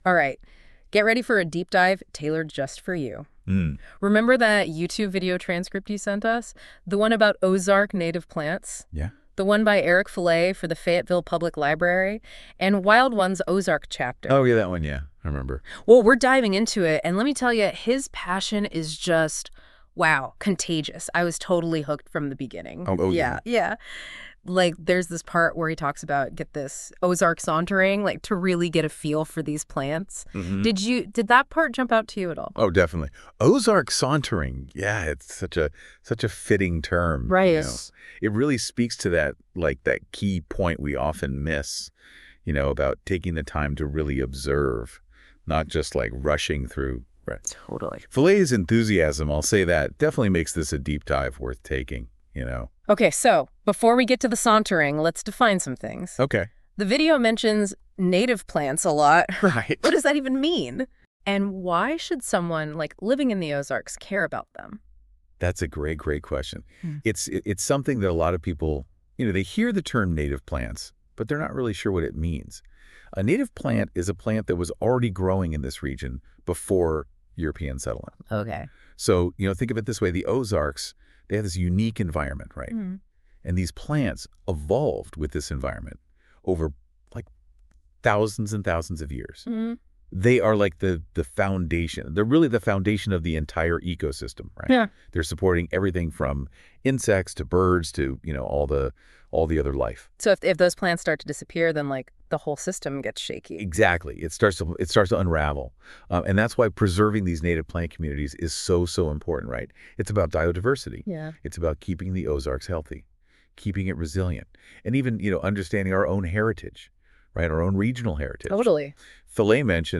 A presentation on Ozark native plants.